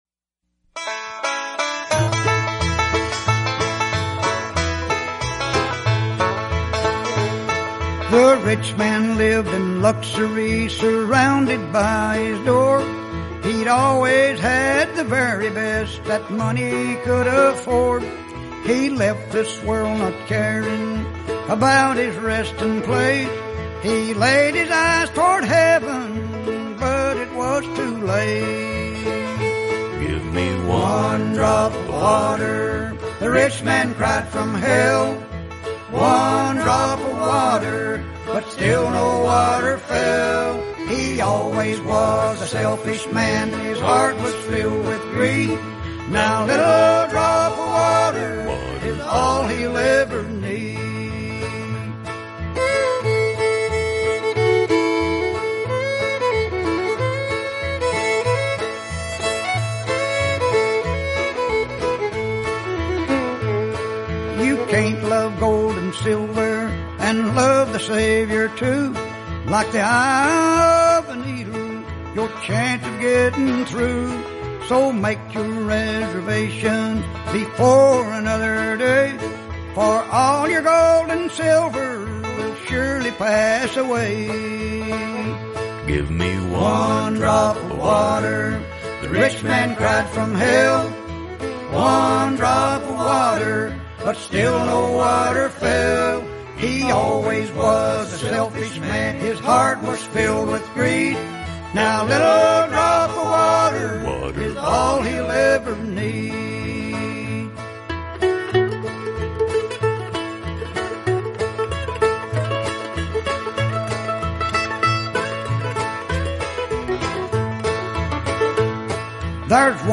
their haunting refrain